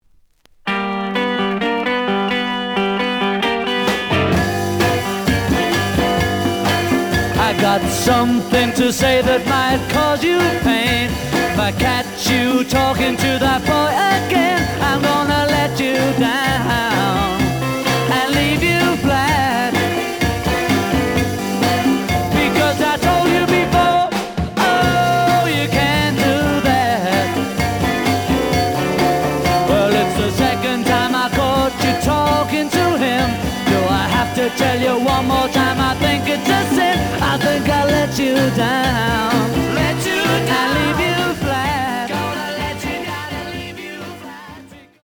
The audio sample is recorded from the actual item.
●Genre: Rock / Pop
Slight edge warp.